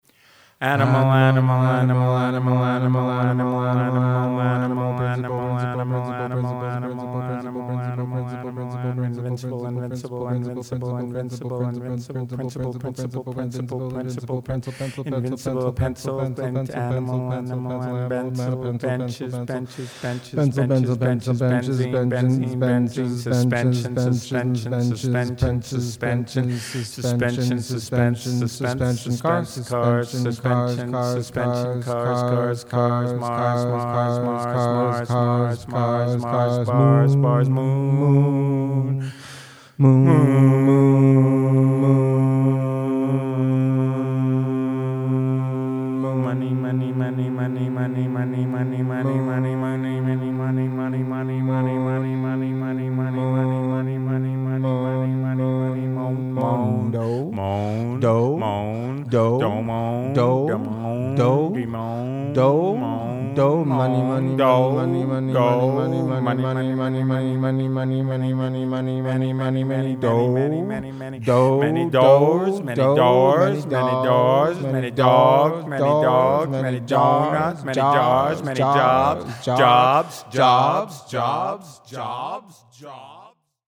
Spoken word improvisation- no predetermined form.